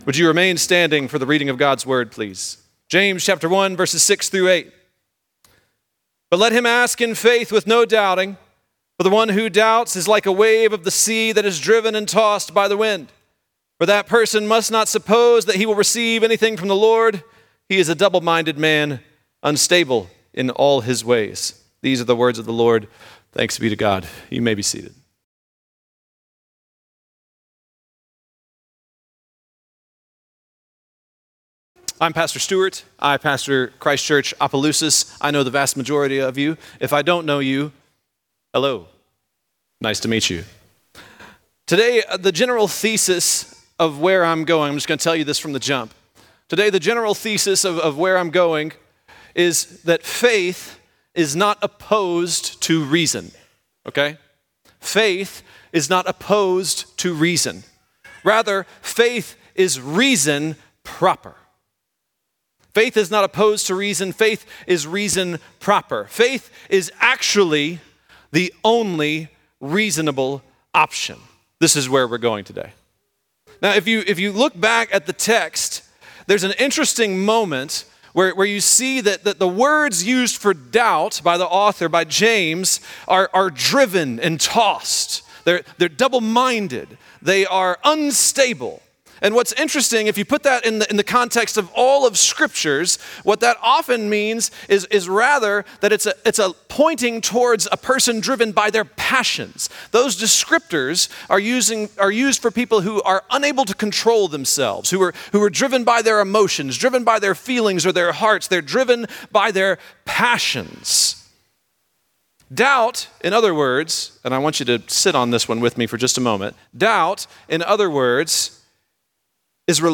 This is a part of our Sunday sermons.